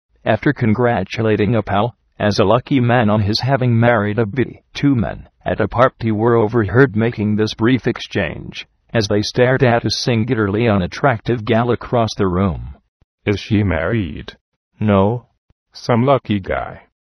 念音